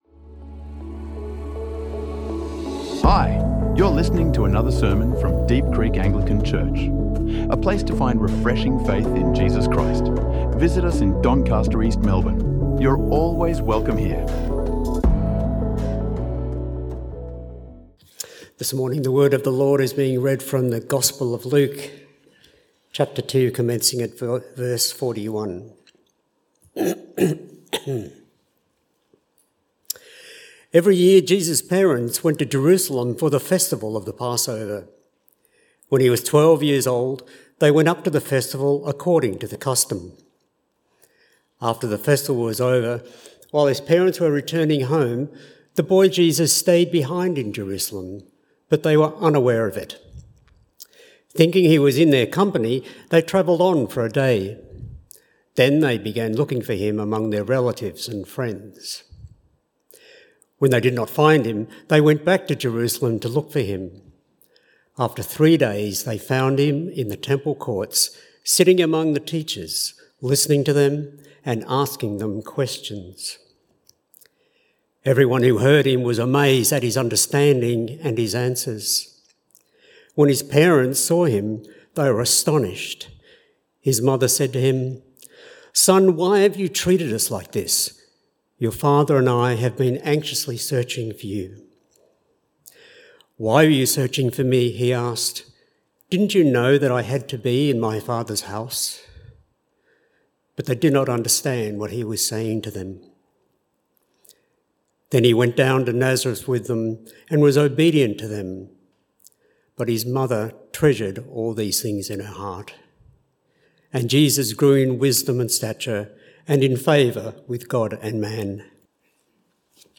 Listen to this powerful Lent sermon today.